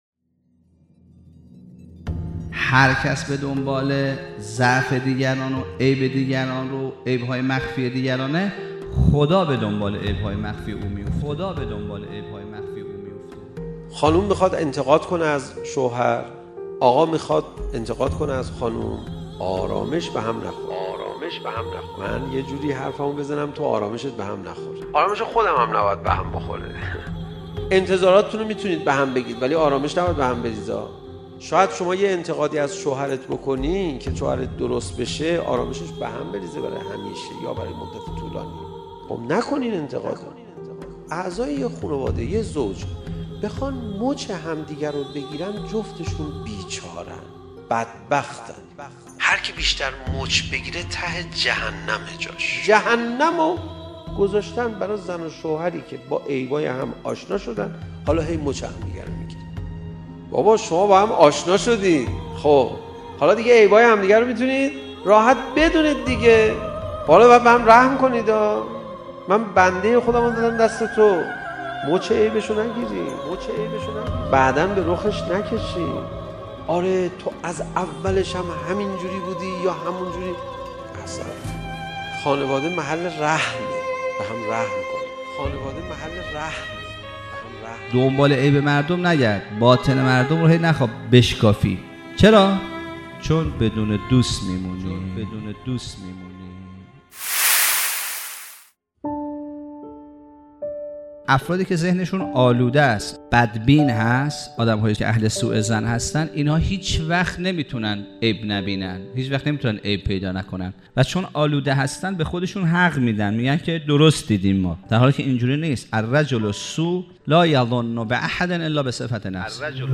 سخنرانی کوتاه